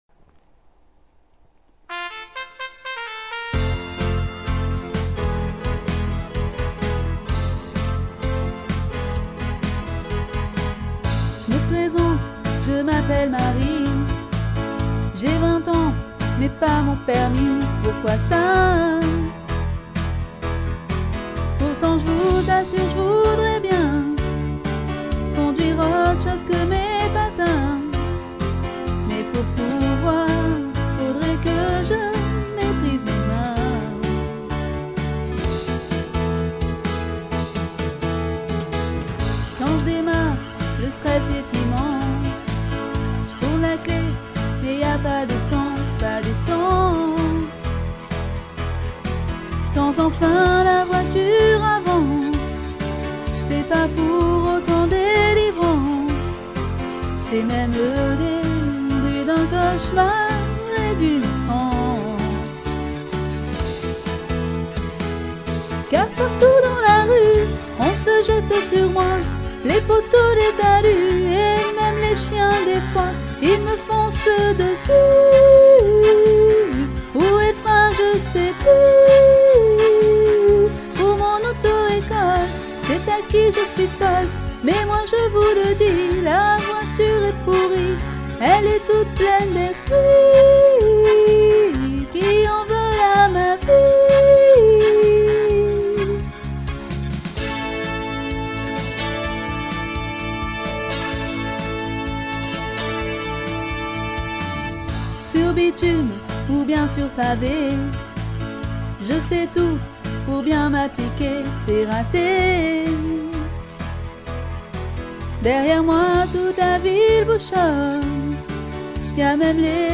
genre variété locale